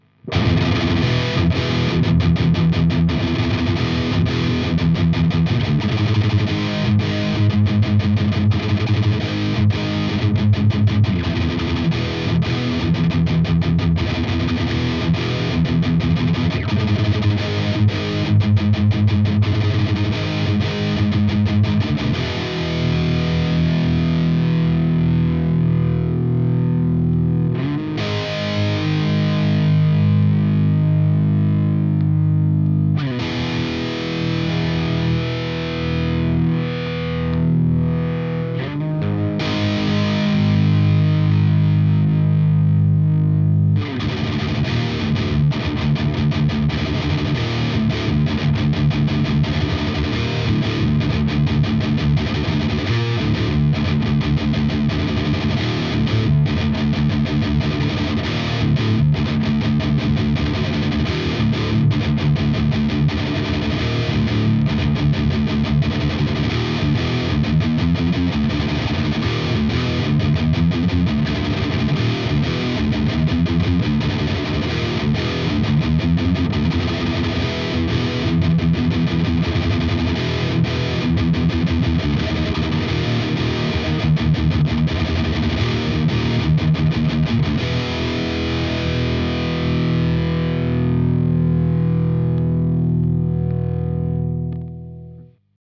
Diodes, 5U4 et GZ34 le tout dans un 57 à 8h15 du matin sans le café du matin qui va bien.
Tout les réglages de la tête sont à 12h.